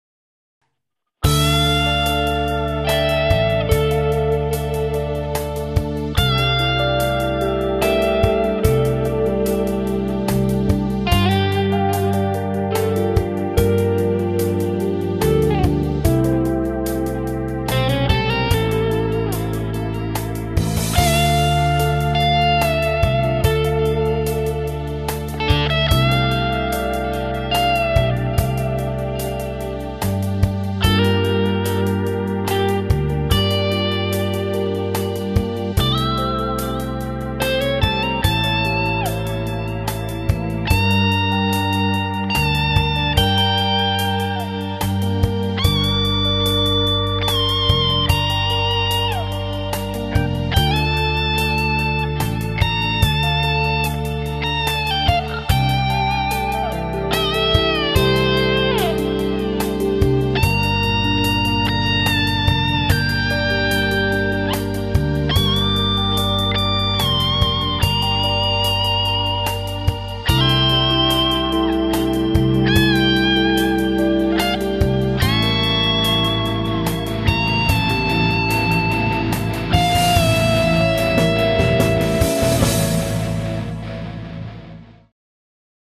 [Ambiance]